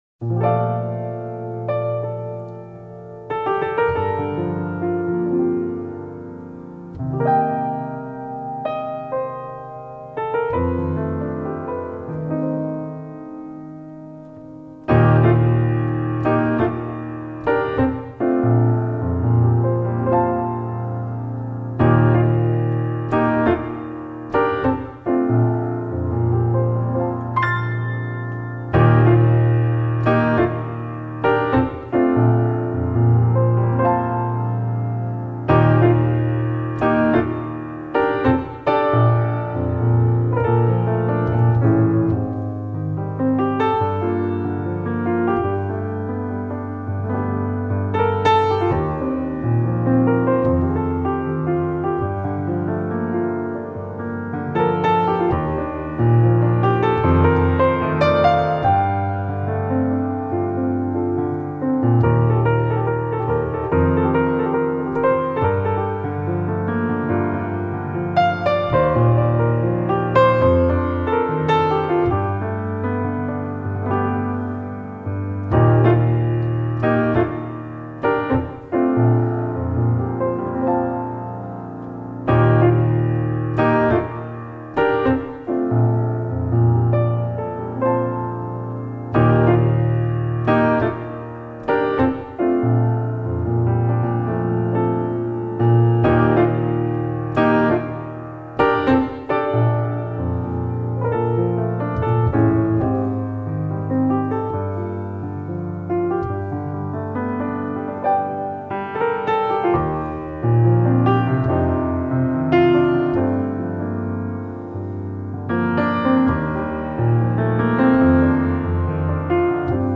piano nocturne